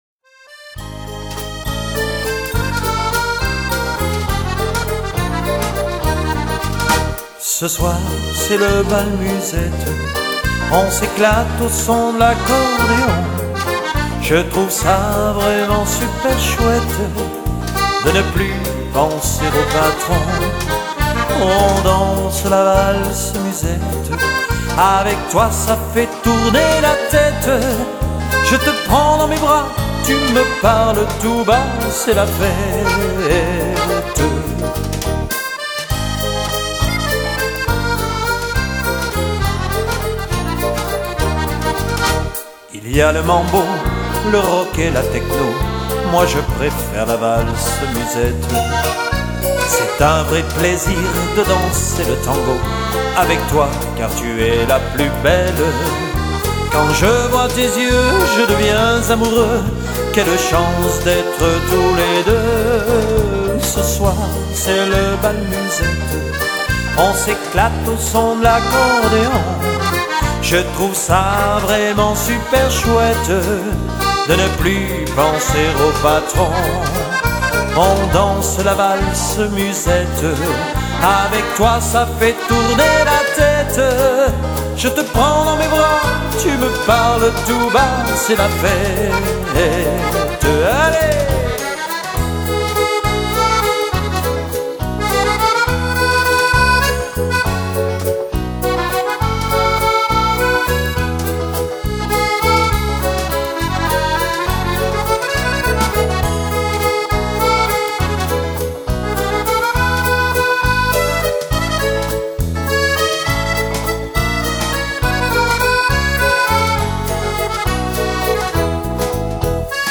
Valses